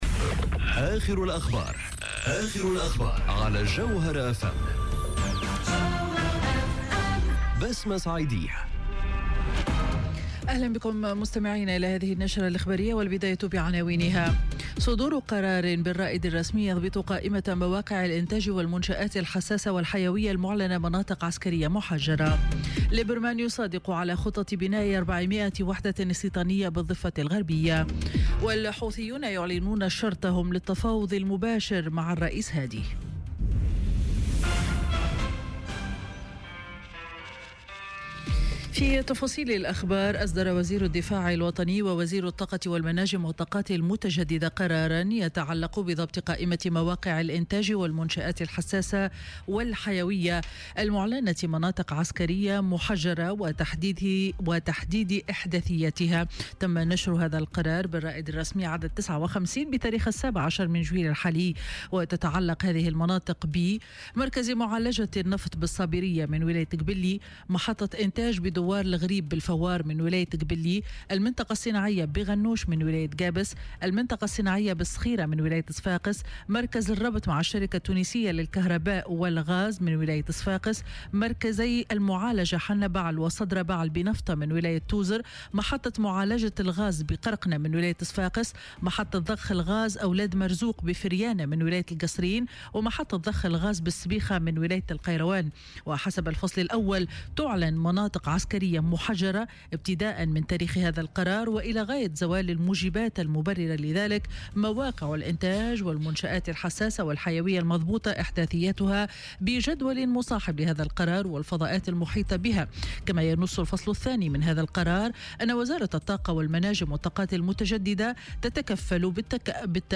نشرة أخبار منتصف النهار ليوم الجمعة 27 جويلية 2018